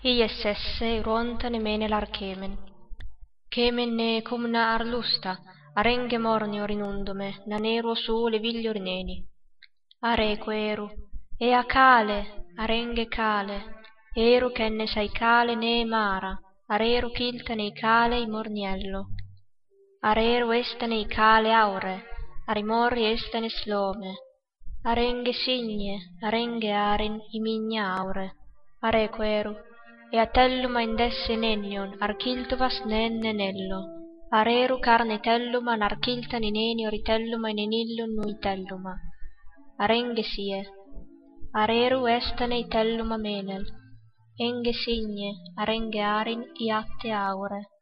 Here’s a recording in a mystery language.